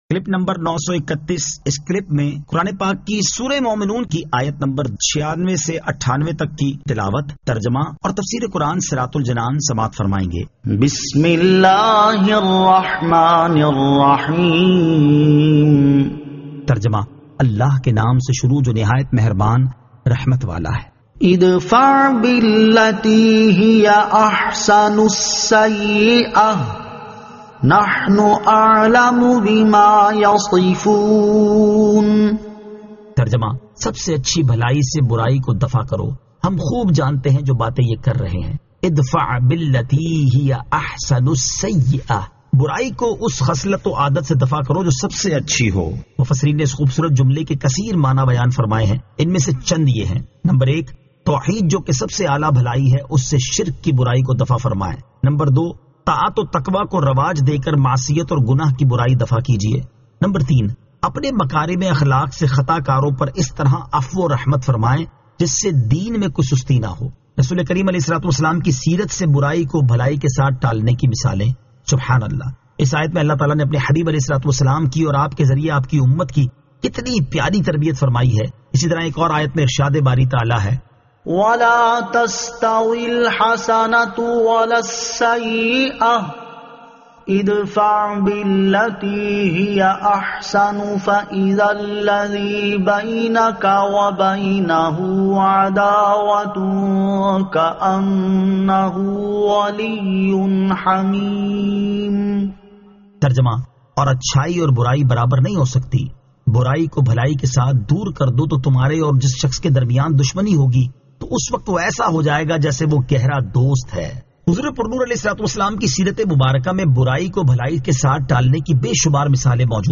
Surah Al-Mu'minun 96 To 98 Tilawat , Tarjama , Tafseer